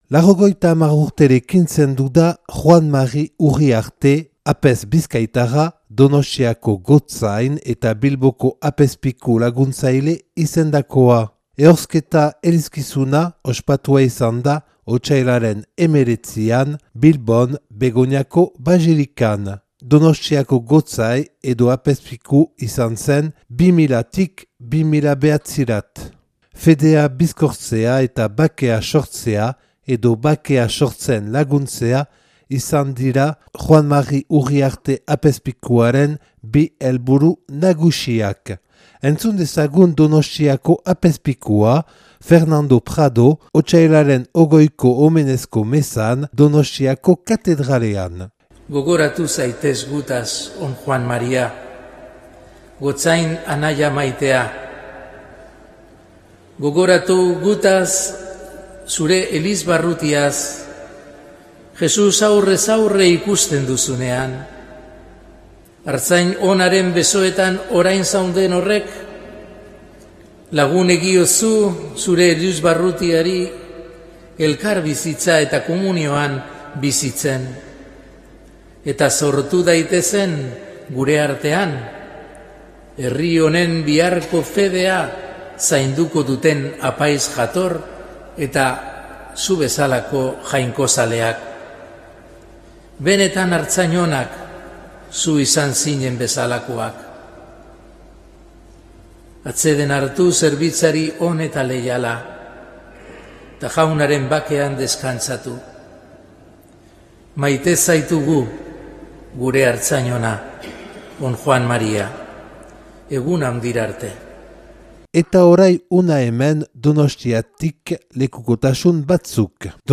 Atxik Berrituz taldeak gomitaturik, Uriarte apezpikuak mintzaldi bat eman zuen Euskal Herriko bakearen alde 2013ko azaroaren 10ean, Belokeko abadian.